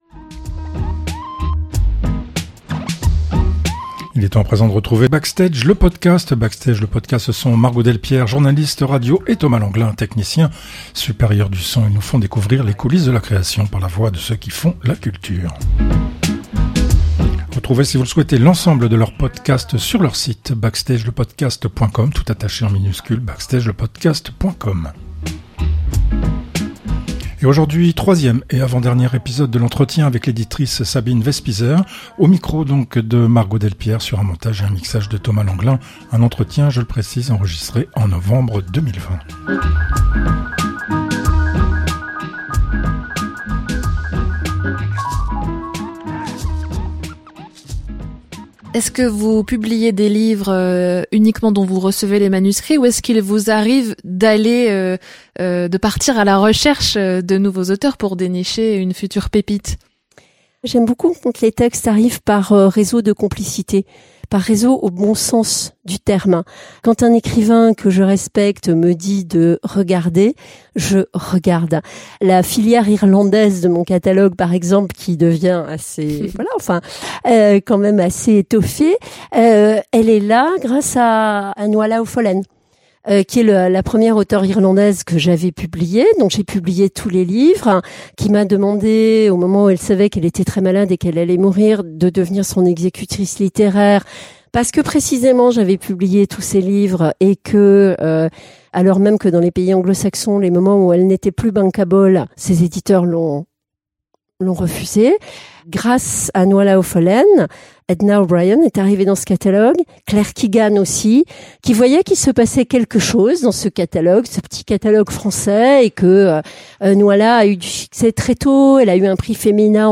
Un entretien enregistré en novembre 2020.